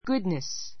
goodness ɡúdnis